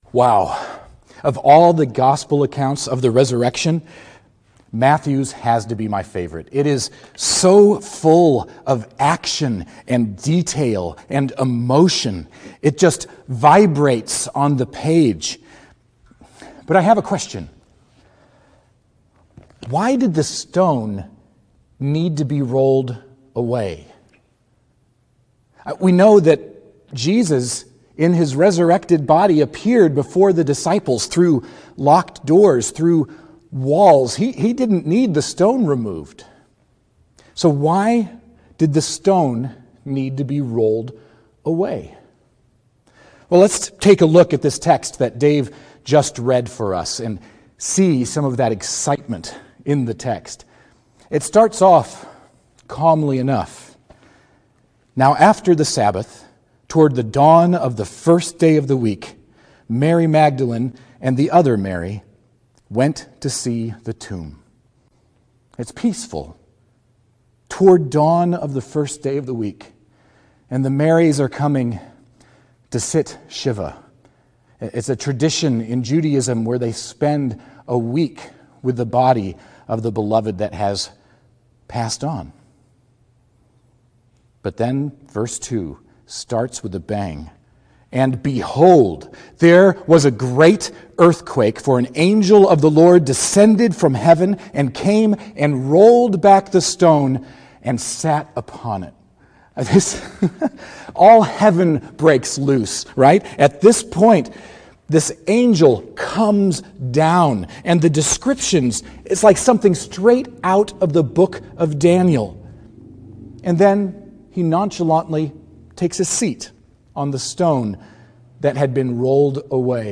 Easter2020Sermon.mp3